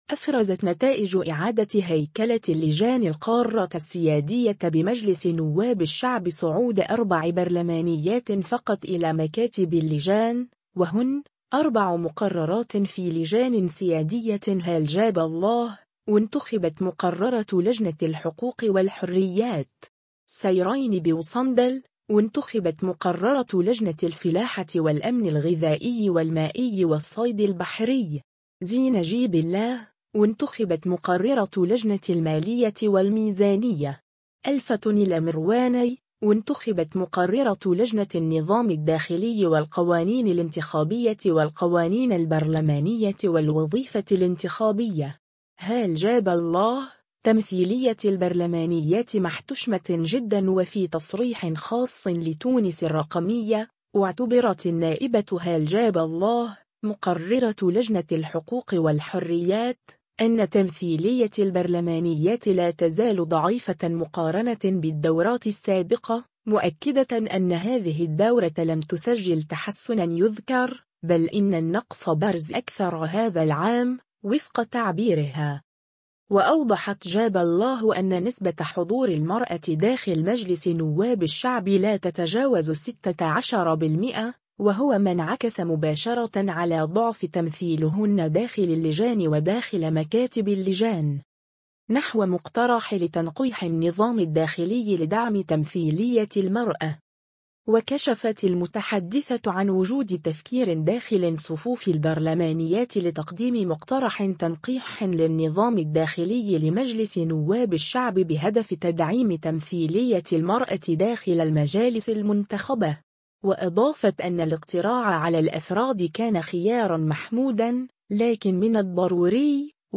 وفي تصريح خاص لـ”تونس الرقمية”، اعتبرت النائبة هالة جاب الله، مقررة لجنة الحقوق والحريات، أن تمثيلية البرلمانيات لا تزال ضعيفة مقارنة بالدورات السابقة، مؤكدة أن هذه الدورة لم تُسجّل تحسنًا يُذكر، بل إن النقص برز أكثر هذا العام، وفق تعبيرها.